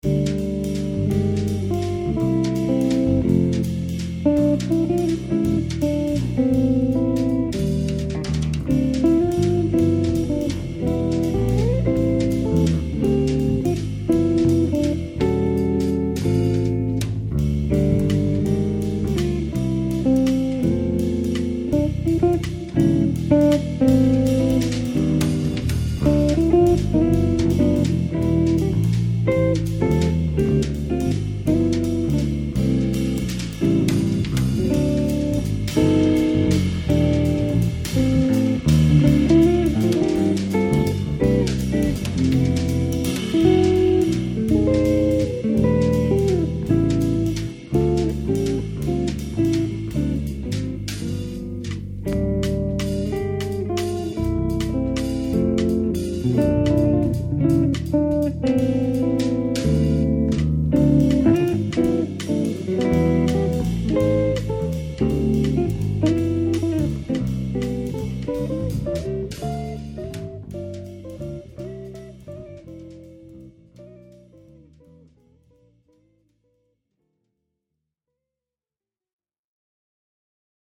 Jazz Waltz